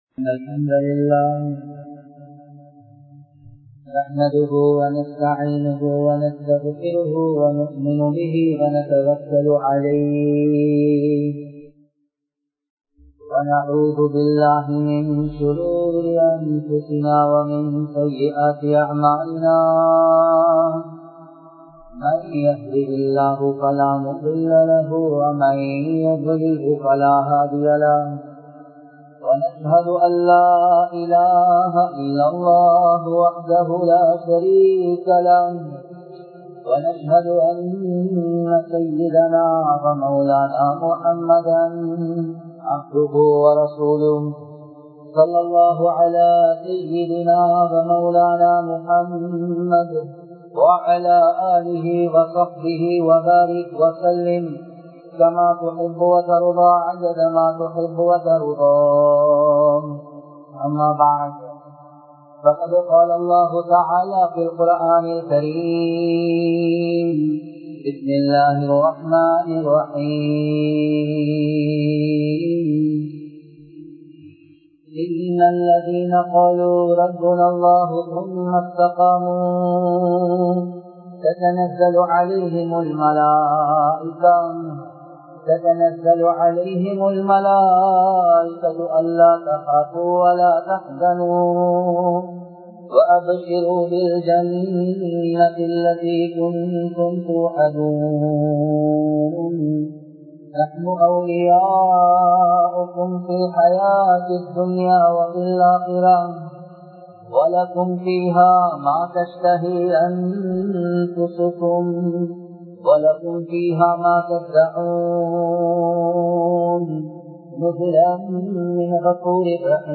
ஹிஜ்ரத் கூறும் படிப்பினைகள் | Audio Bayans | All Ceylon Muslim Youth Community | Addalaichenai